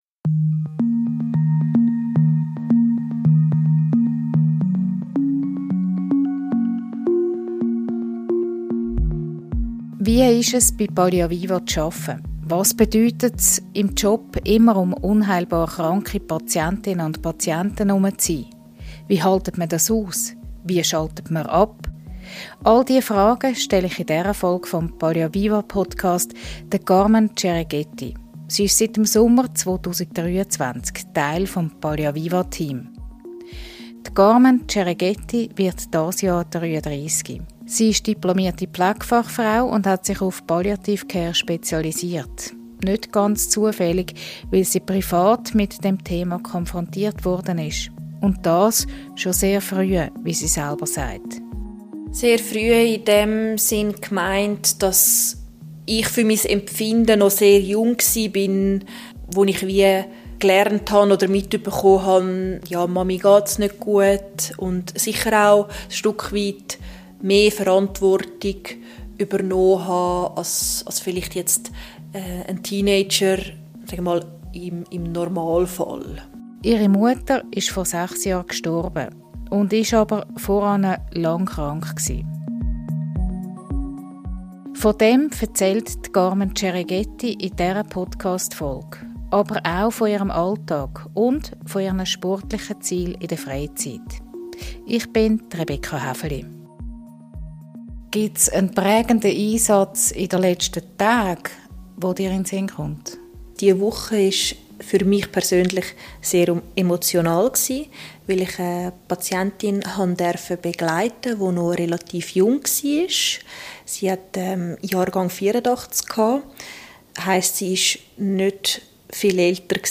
Ein Gespräch über Work-Life-B...